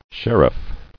[sher·iff]